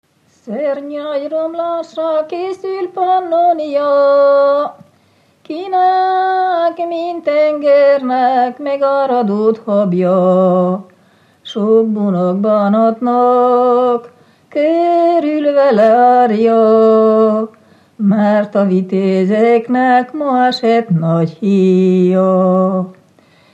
Moldva és Bukovina - Bukovina - Józseffalva
Műfaj: Históriás ének
Stílus: 4. Sirató stílusú dallamok
Kadencia: 5 (1) b3 1